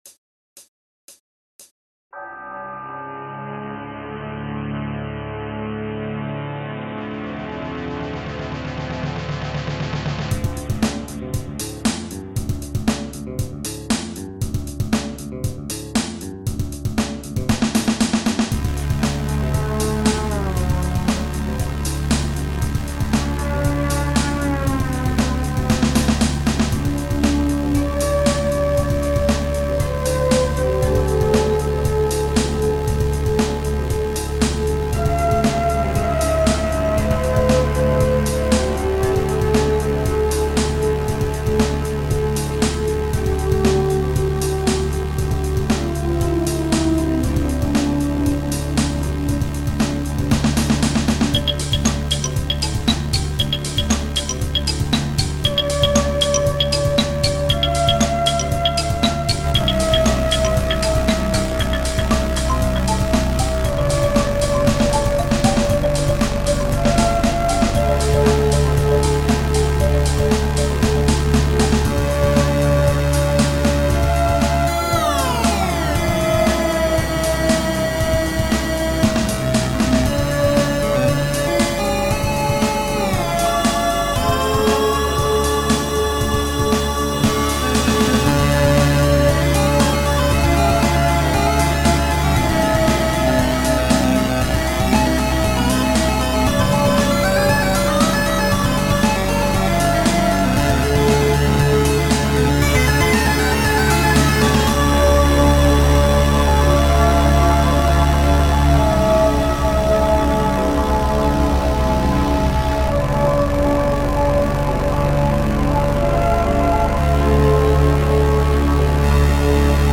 An steadily intensifying cover